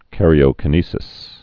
(kărē-ō-kə-nēsĭs, -kī-)